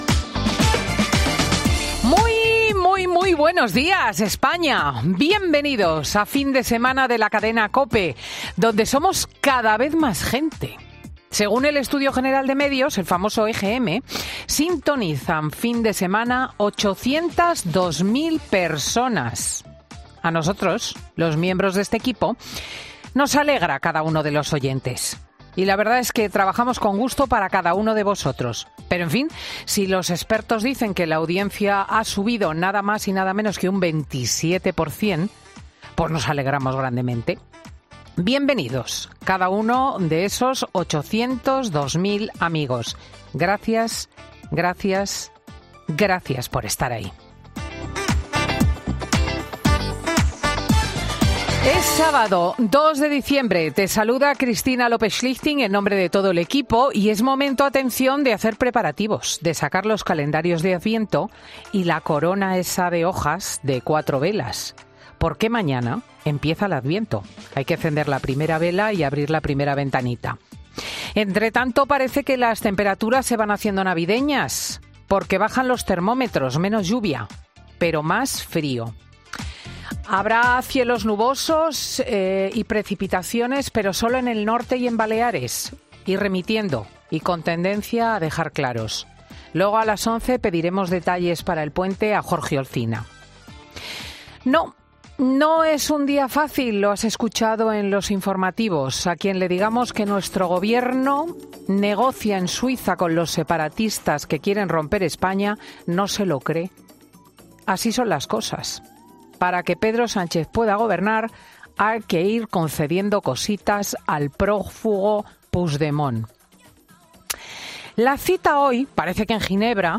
AUDIO: Ya puedes escuchar el monólogo de Cristina López Schlichting de este sábado 2 de diciembre de 2023